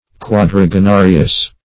Search Result for " quadragenarious" : The Collaborative International Dictionary of English v.0.48: Quadragenarious \Quad`ra*ge*na"ri*ous\, a. [L. quadragenarius, fr. qyadrageni forty each.] Consisting of forty; forty years old.
quadragenarious.mp3